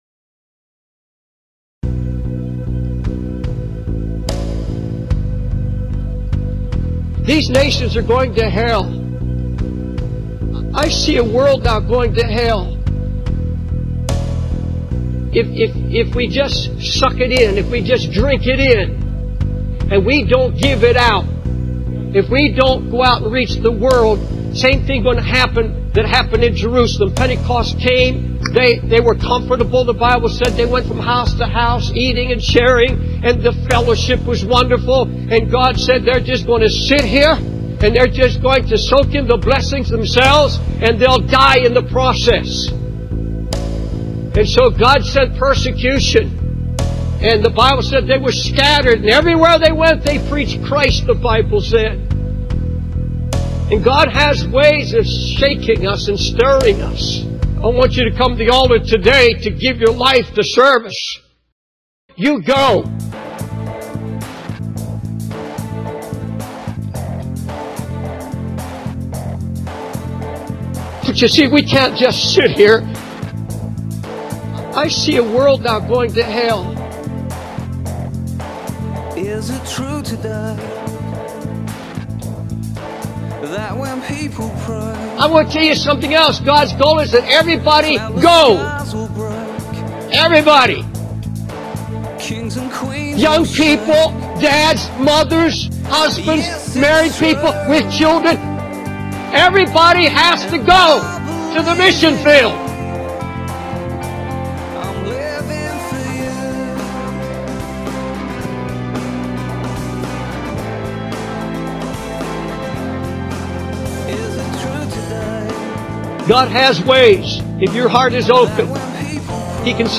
David Wilkerson passionately urges believers to take action and reach out to the nations, emphasizing that many are heading to hell while Christians remain comfortable and complacent. He draws parallels to the early church at Pentecost, warning that without proactive outreach, God may use challenges to stir us into action.
Sermon Outline